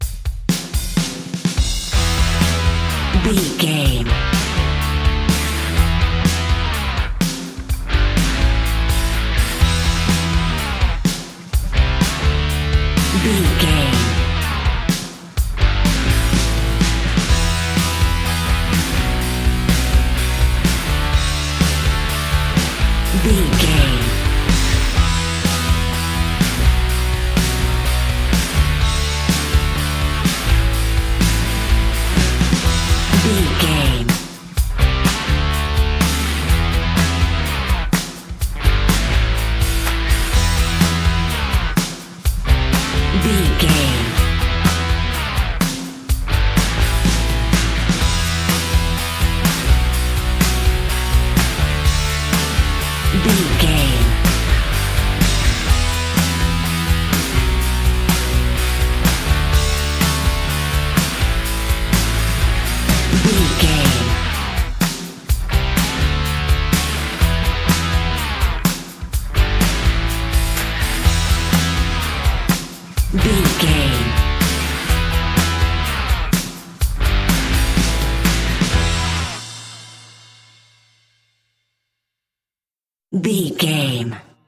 Mixolydian
electric guitar
bass guitar
drums
hard rock
aggressive
energetic
intense
nu metal
alternative metal